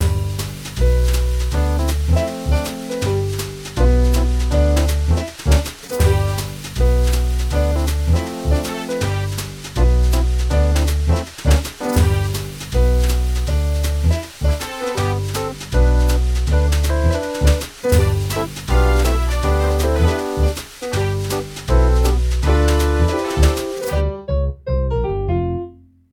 swing
piano
batería
melodía